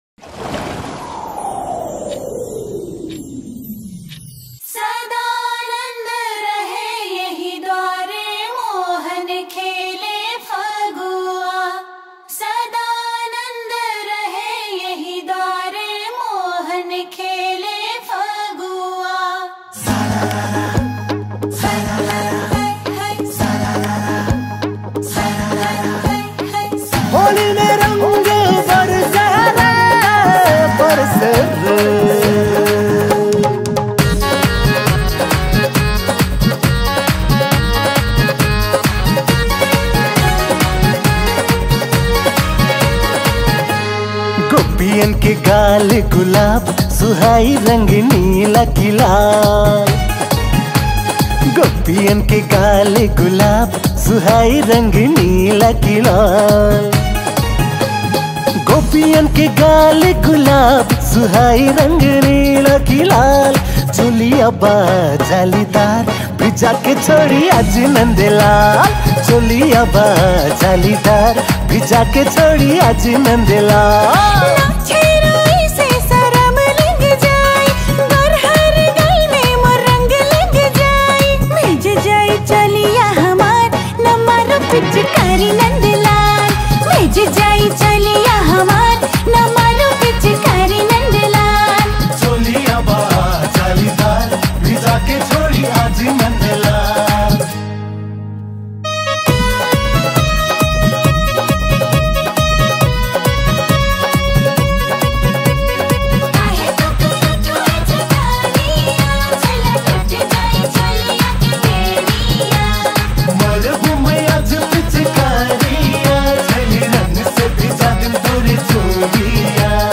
Tharu Culture Song